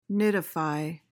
PRONUNCIATION: (NID-uh-fy) MEANING: verb intr.: To build a nest.